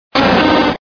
Cri de Cochignon dans Pokémon Diamant et Perle.